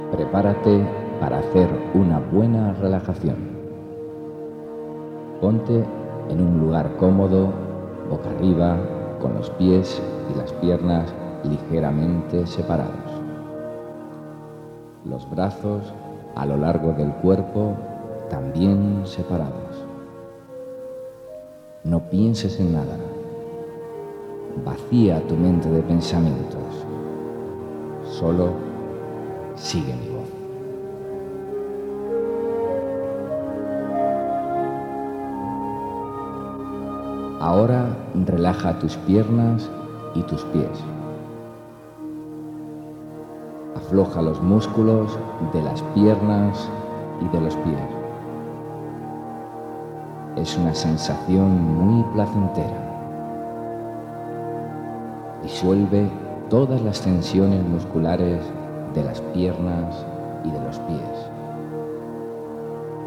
Si se usan con un reproductor de sonido, es conveniente oírlos usando auriculares, ya que existen ligeras diferencias entre la frecuencia que percibe un oído y el otro, para aumentar su eficacia y esto se pierde si se escuchan a través de altavoces.